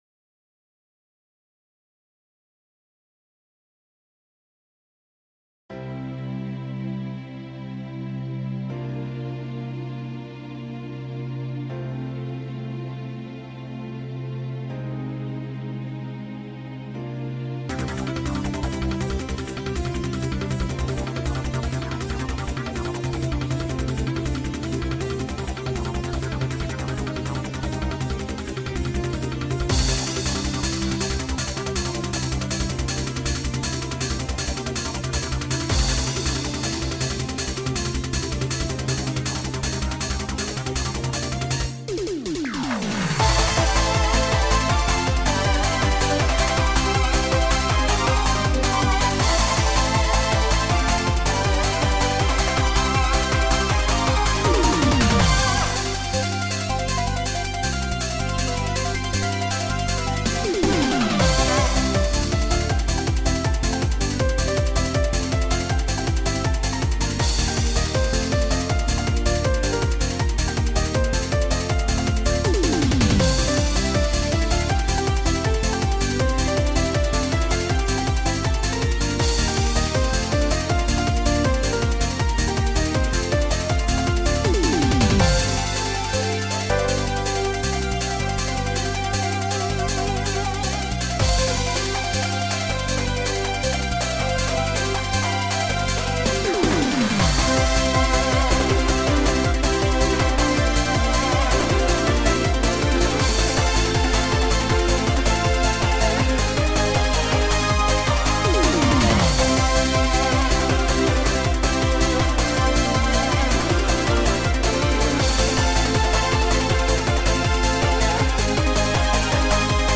genre:eurobeat